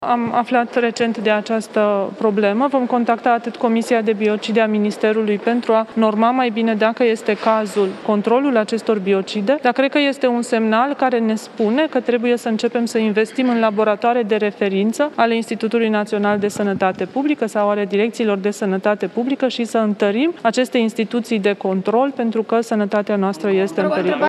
Trebuie să întărim instituţiile de control ale statului – este declaraţia ministrului Sănătăţii, Ioana Mihăilă, după o investigaţie a presei locale din Sibiu privind biocide diluate.